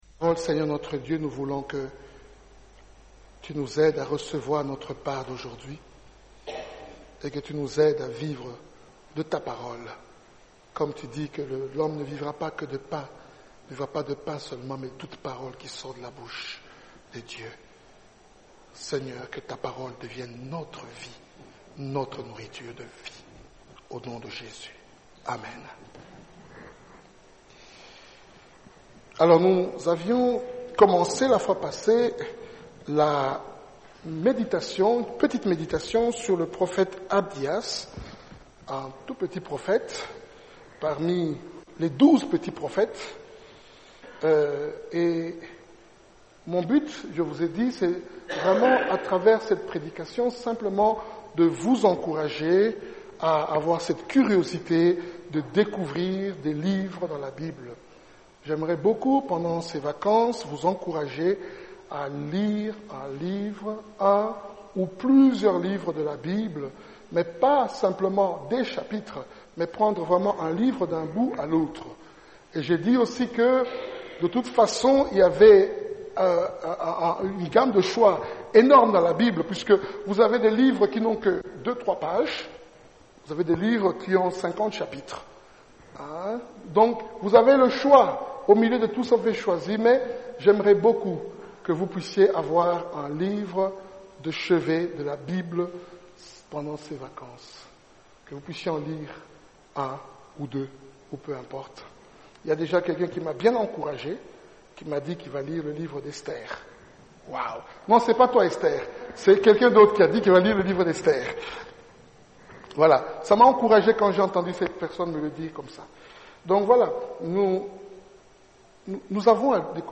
Type De Service: Dimanche matin